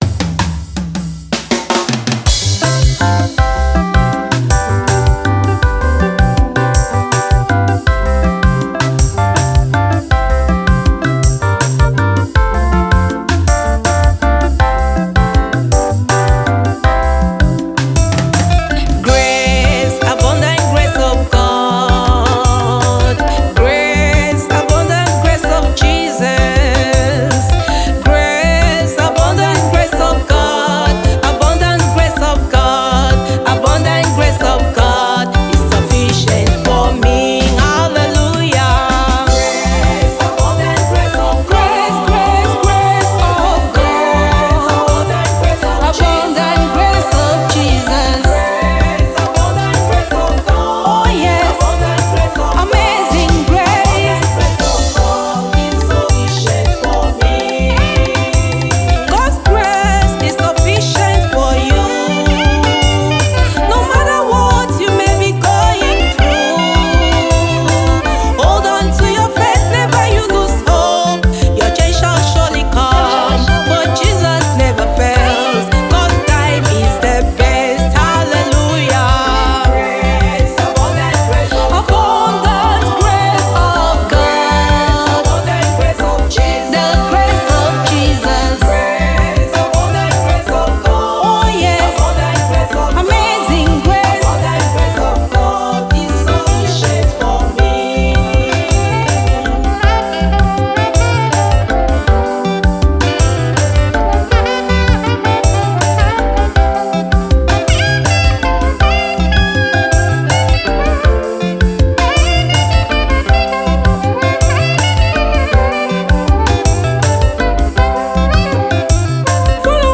Gospel Music